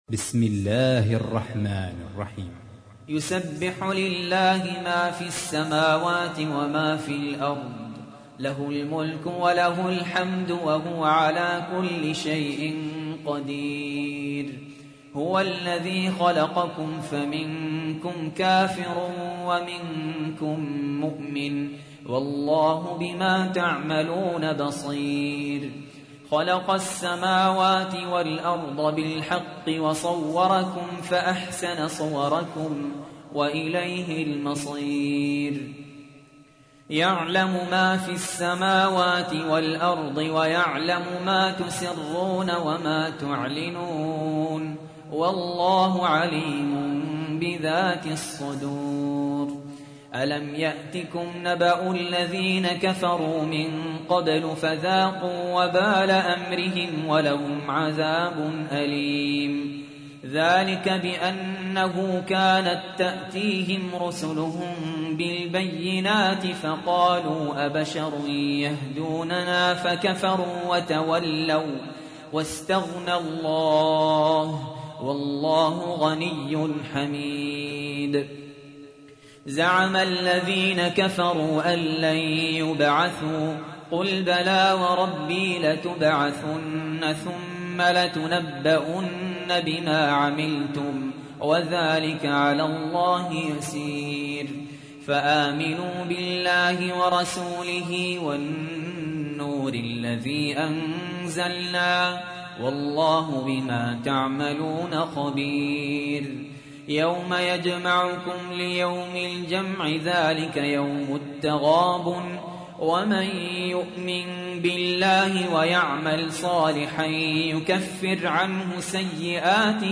تحميل : 64. سورة التغابن / القارئ سهل ياسين / القرآن الكريم / موقع يا حسين